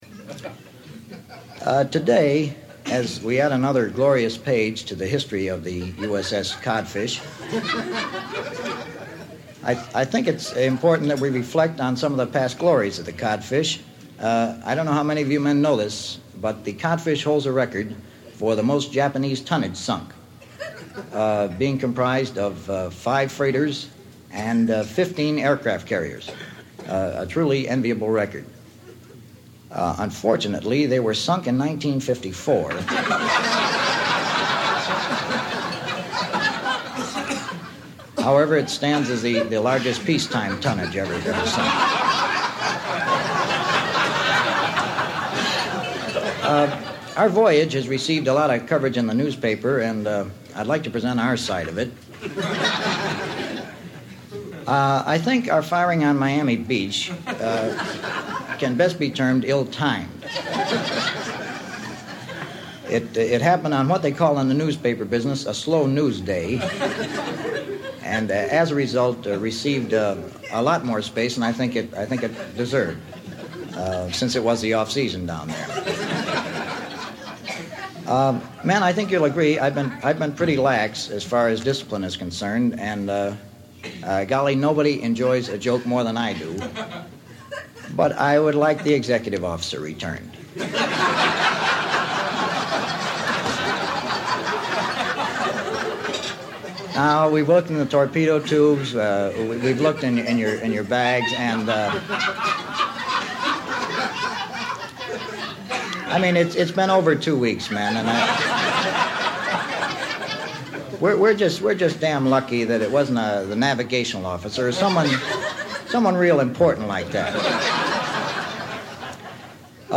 It’s especially fun to hear Newhart as a young man, telling jokes to what sounds like an audience of three dozen (cutlery is audible in the background).